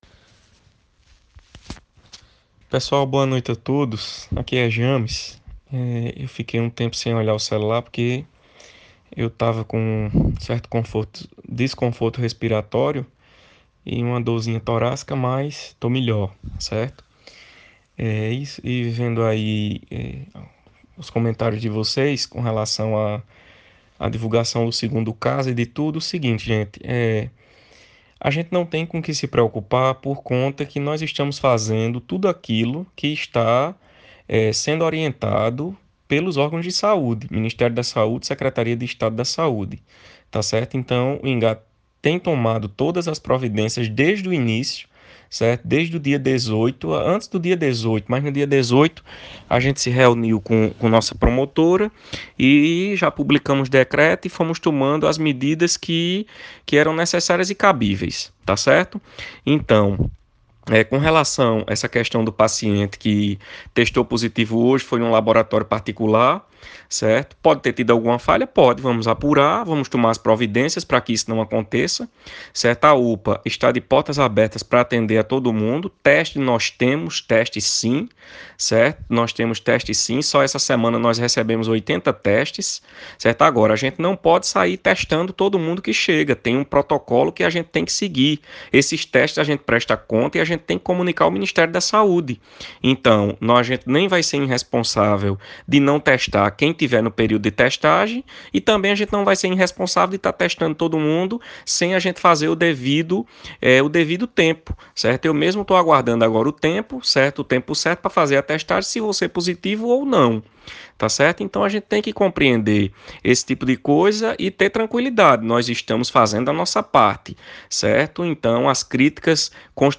ÁUDIO: Secretário de Saúde de Ingá fala a respeito do combate ao coronavírus, atendimento na UPA e trabalho da equipe de saúde
Ouça a fala do secretário Jammes Araújo, que no momento se encontra em quarentena com sintomas gripais, mas em contato permanente com toda equipe de saúde de Ingá.